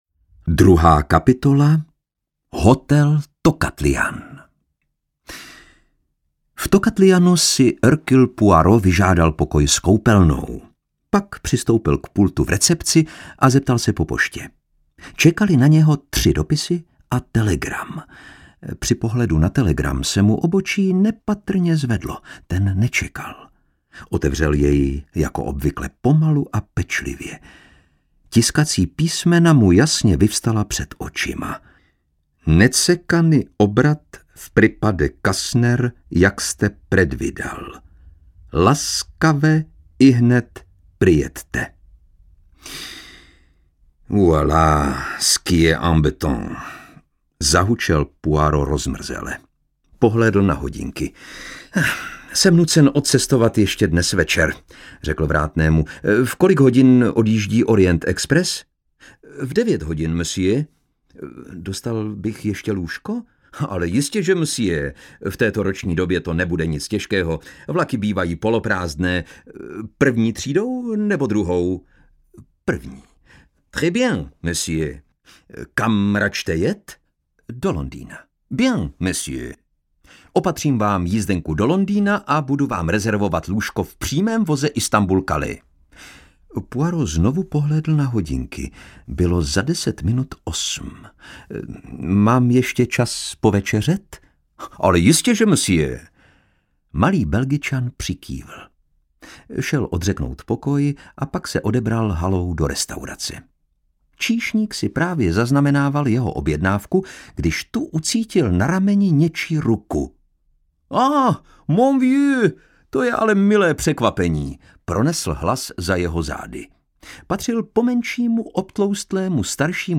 Audiobook
Read: Lukáš Hlavica